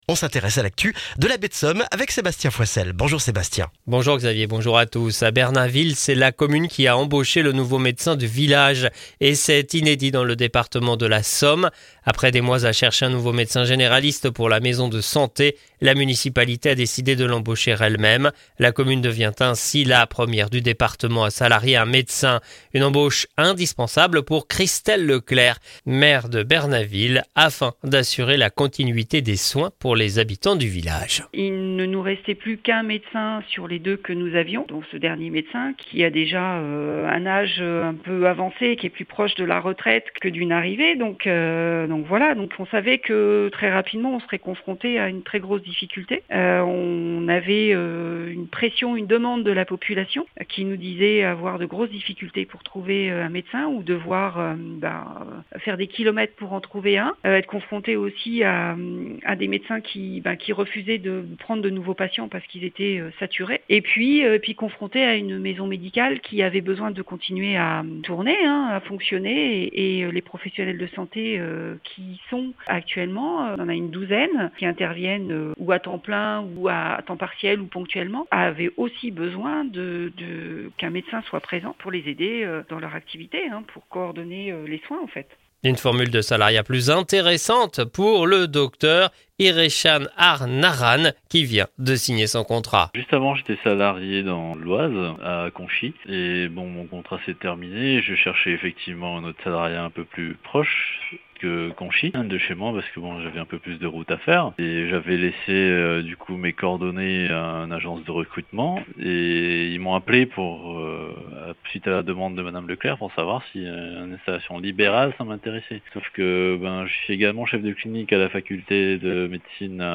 Le journal du mardi 5 novembre en Baie de Somme et dans la région d'Abbeville